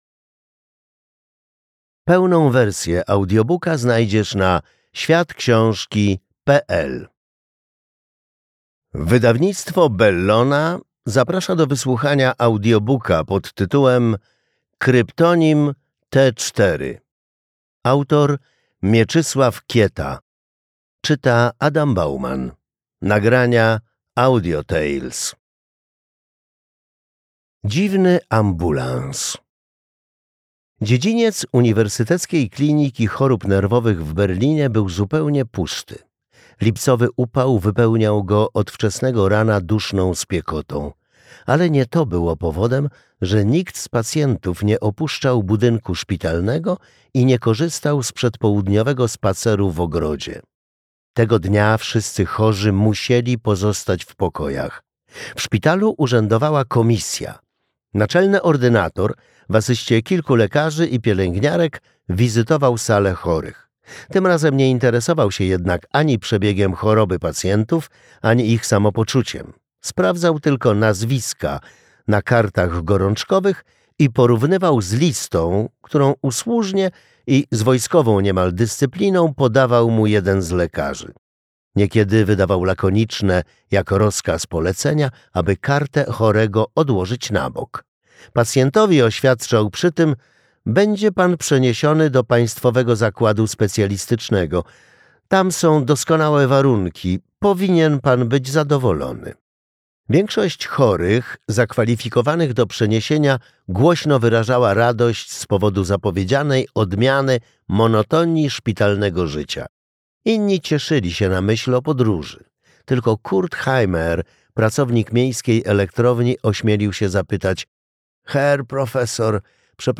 Kryptonim T-4 - Mieczysław Kieta - audiobook